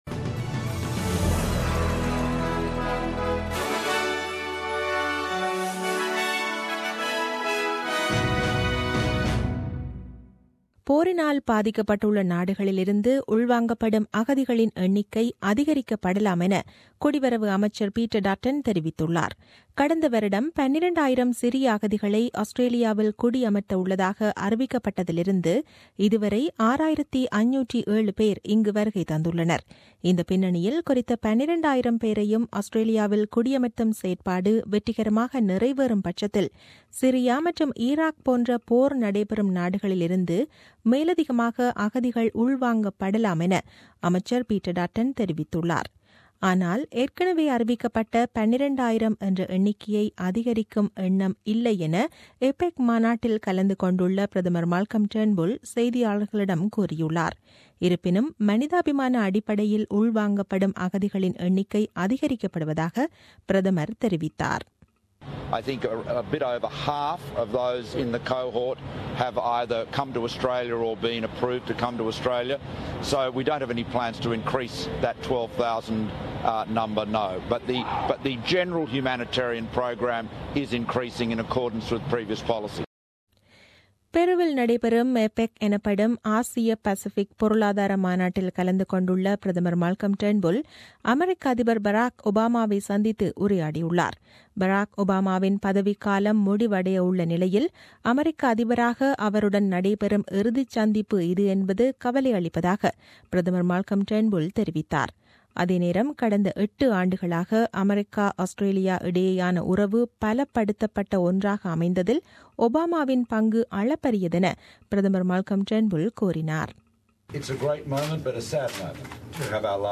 The news bulletin aired on 21 Nov 2016 at 8pm.